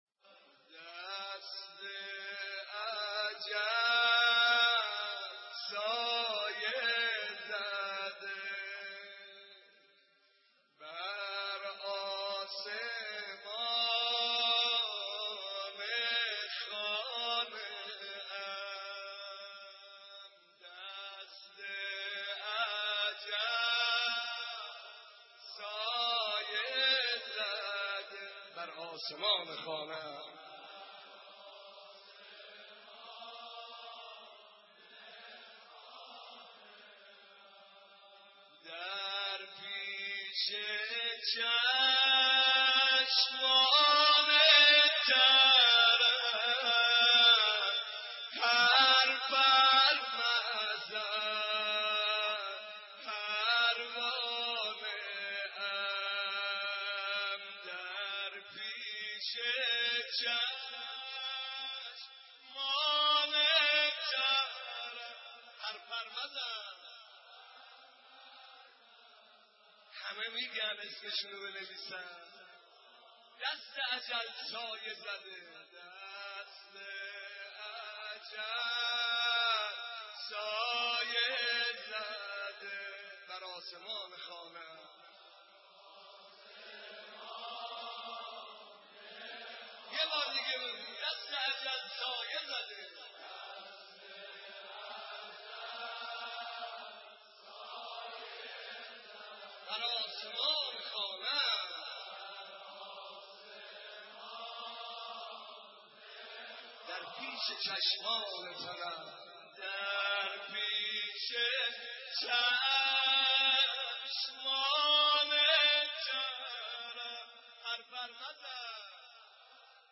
دانلود مداحی دست اجل سایه زده - دانلود ریمیکس و آهنگ جدید
مرثیه خوانی در مقام حضرت زهرا (س)